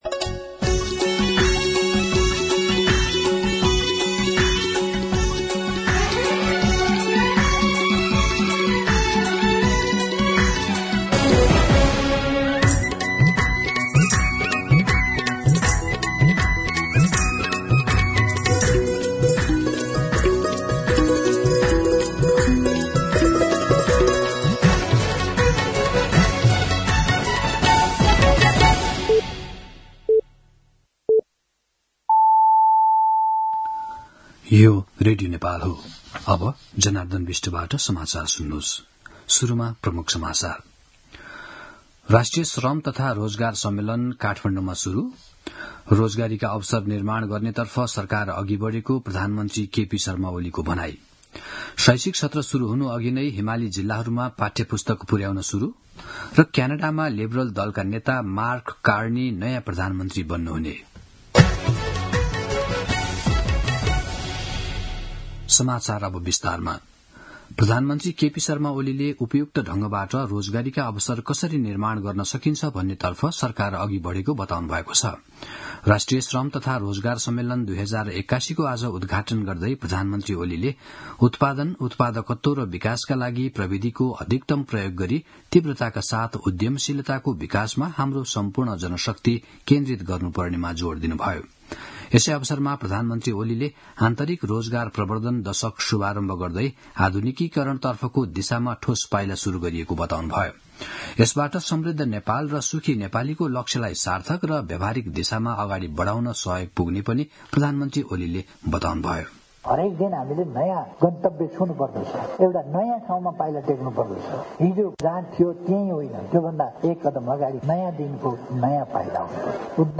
दिउँसो ३ बजेको नेपाली समाचार : २७ फागुन , २०८१
3pm-News-11-26.mp3